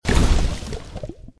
Add footstep sounds for water and lava
default_lava_footstep.1.ogg